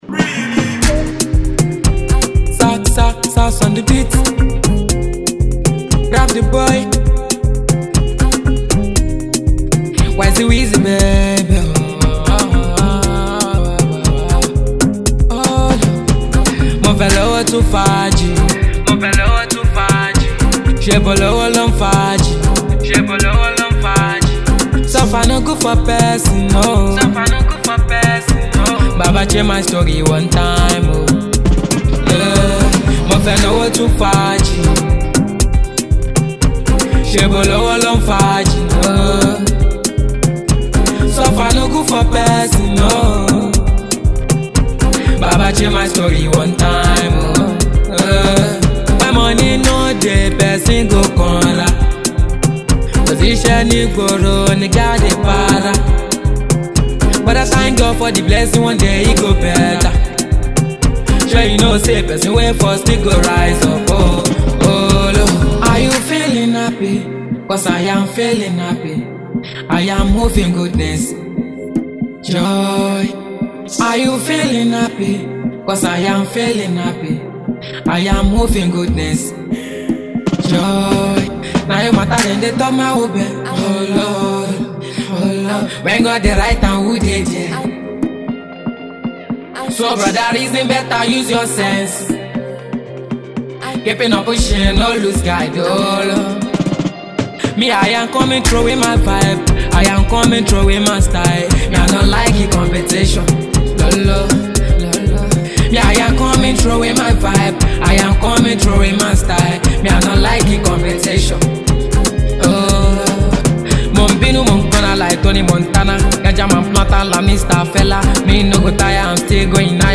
The afro hip hop singer